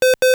LogOn.wav